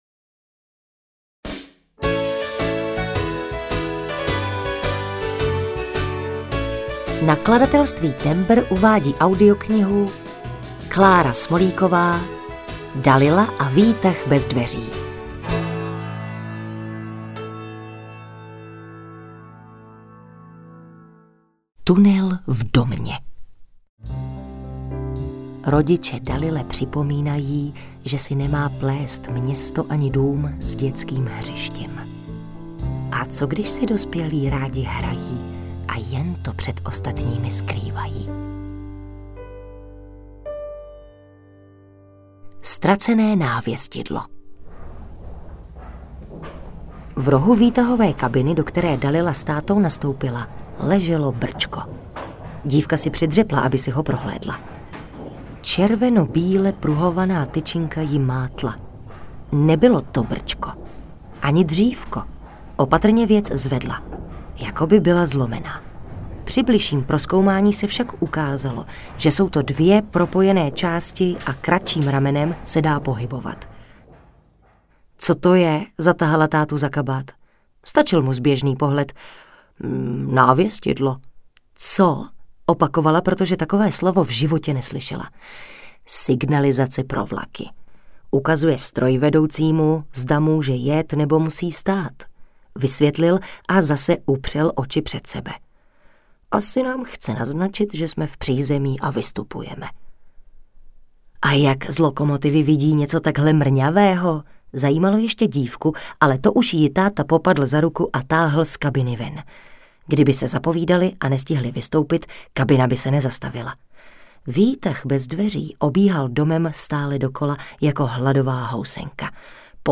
Dalila a výtah bez dveří audiokniha
Ukázka z knihy
Hudba Karpof Brothers | Natočeno ve studiu KARPOFON (AudioStory)